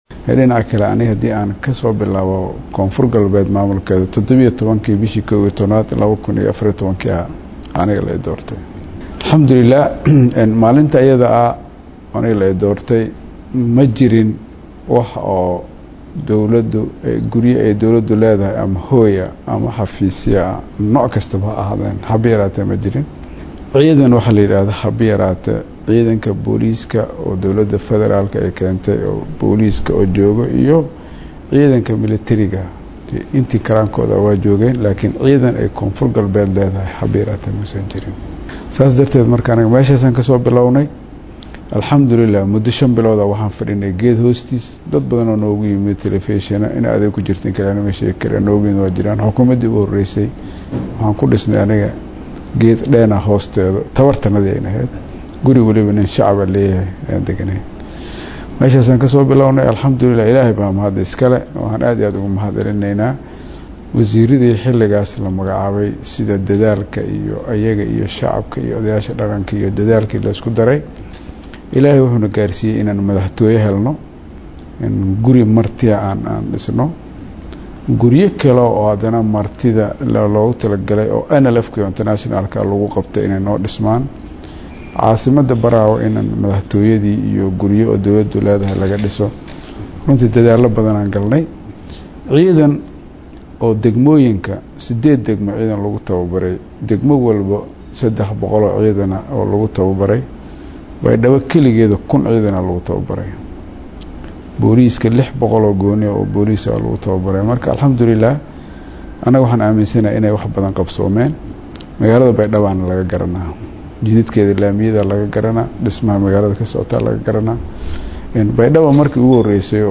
Dhageyso-Wareysi-MadaxweynahaDowladaGoboleedka-KGS-shariifxasan-sheikh.mp3